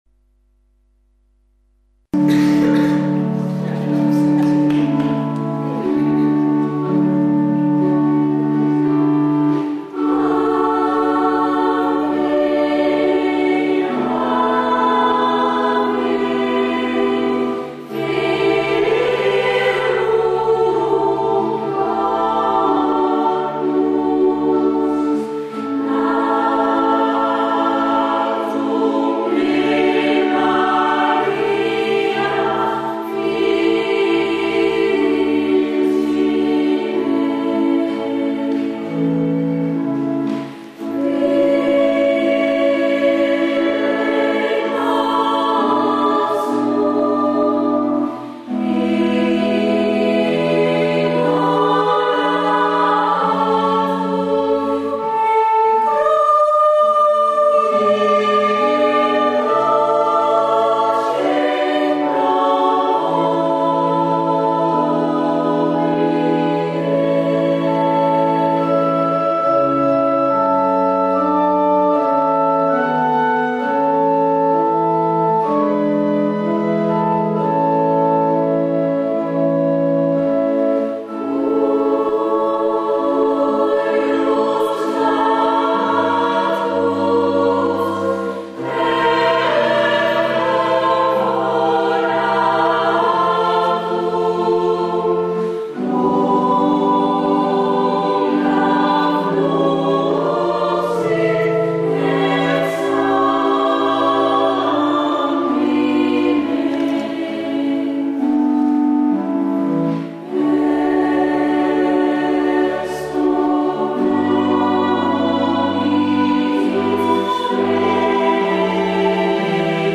kerkkoor
Hieronder een aantal opnamen van het kerkkoor, gemaakt door